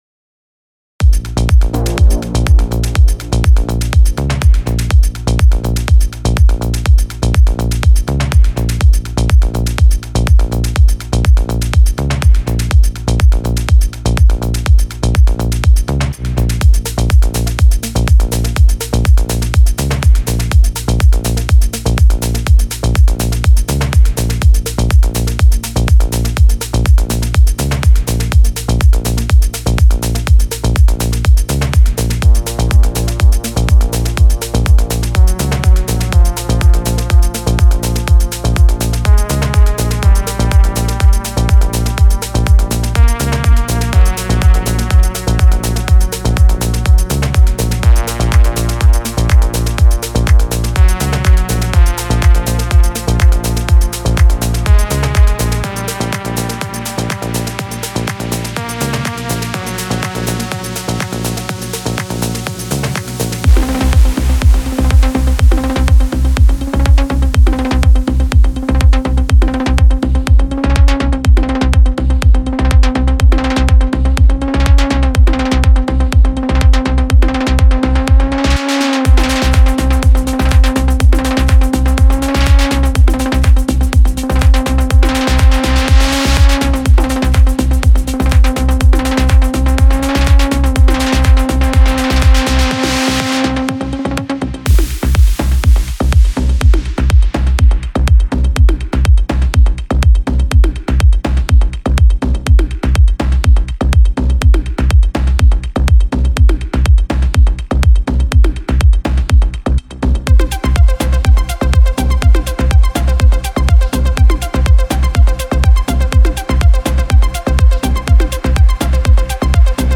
Genre:Progressive House
豊かなハーモニー、催眠的なグルーヴ、表現力豊かなメロディックテクスチャ、そして推進力のある低域エネルギーです。
デモサウンドはコチラ↓
111 Drum Part Loops
55 Bass Loops
36 Atmosphere Loops
30 Synth Lead Loops